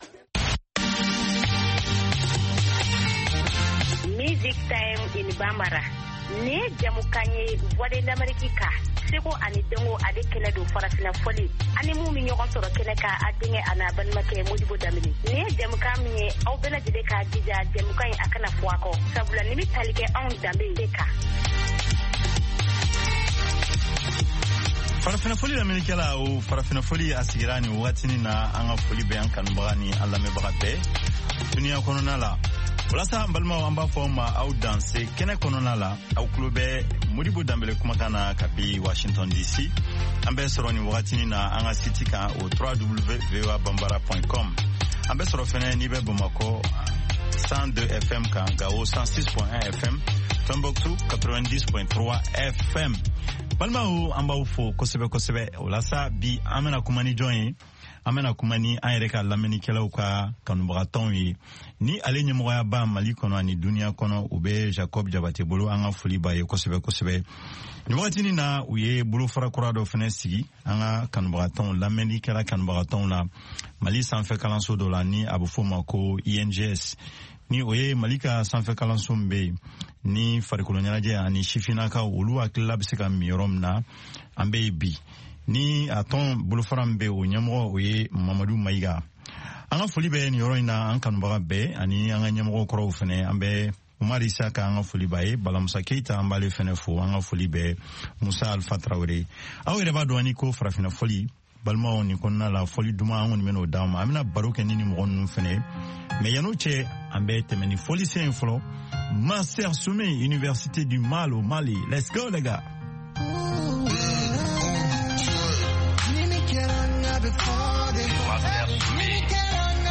Bulletin d’information de 17 heures